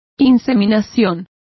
Complete with pronunciation of the translation of insemination.